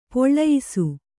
♪ poḷḷayisu